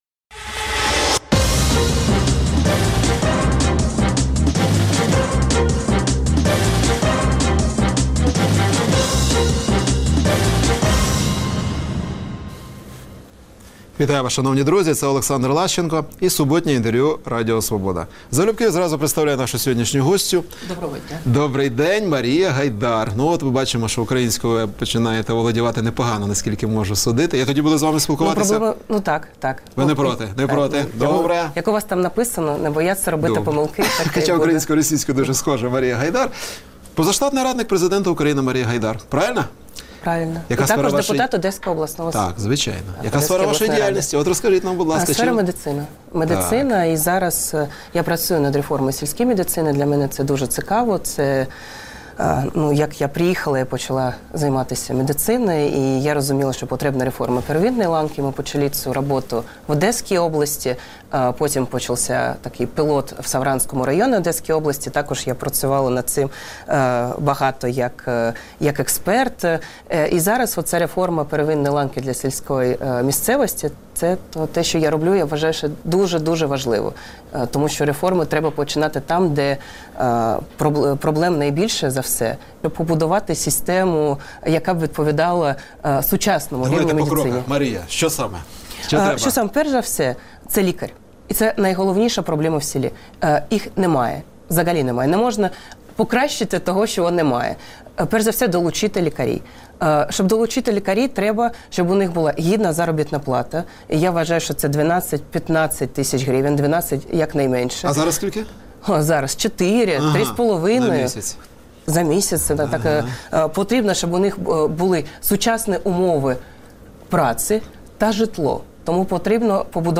Суботнє інтерв’ю | Марія Гайдар, радниця президента України
Суботнє інтвер’ю - розмова про актуальні проблеми тижня.